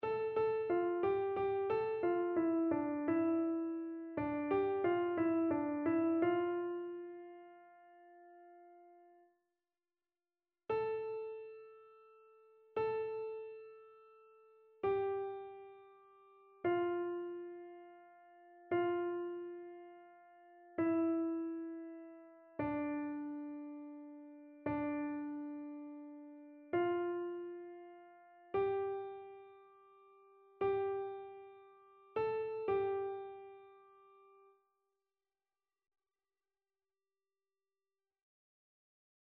Chœur
annee-a-temps-ordinaire-28e-dimanche-psaume-22-soprano.mp3